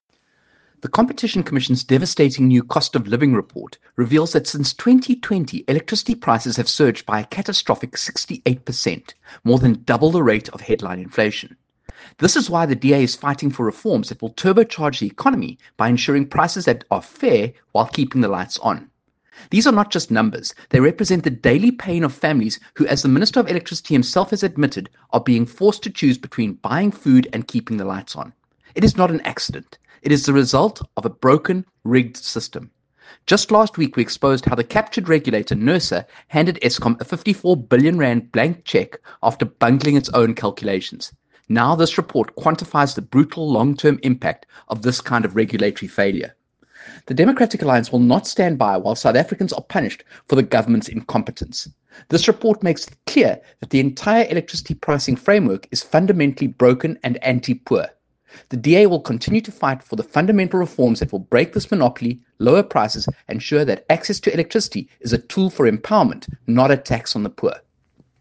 Issued by Kevin Mileham MP – DA Spokesperson for Electricity and Energy
Soundbite by Kevin Mileham